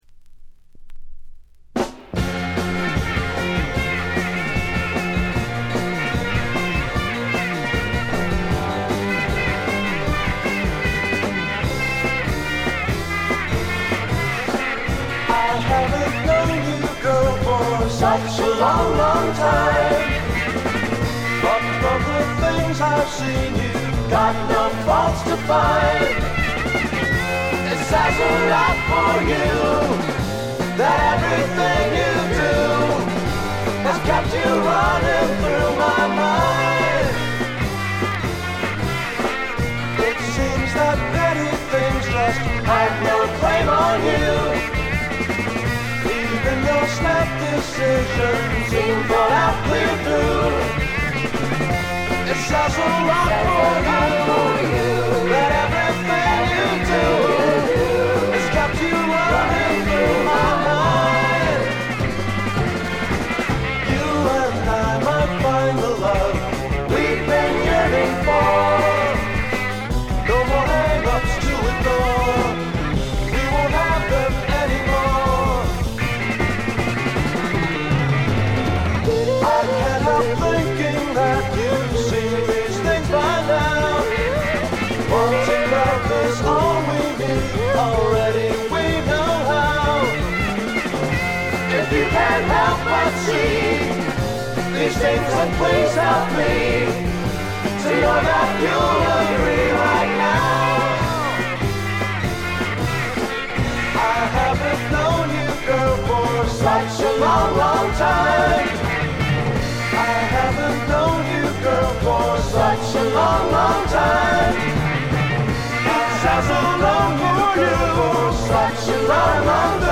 ほとんどノイズ感無し。
個人的にはギターの音色がなかなかアシッド／サイケしていてかなりつぼに来ます。
試聴曲は現品からの取り込み音源です。